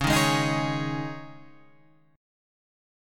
Dbsus2 chord